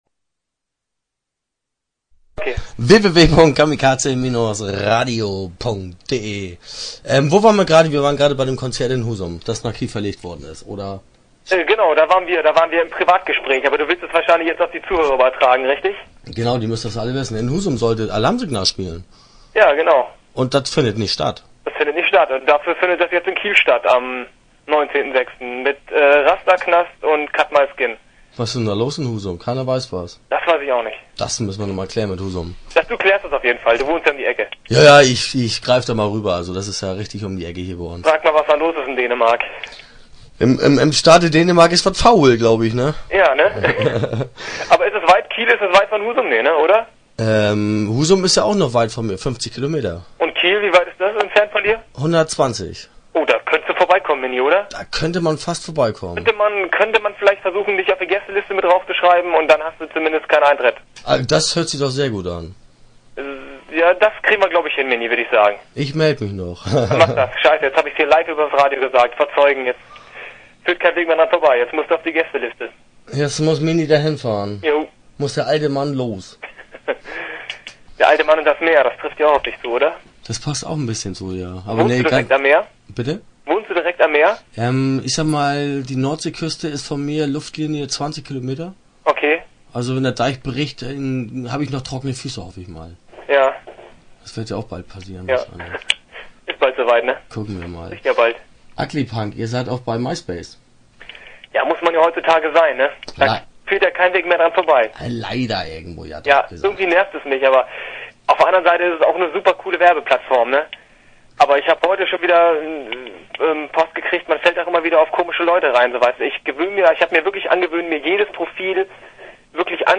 Start » Interviews » Ugly-Punk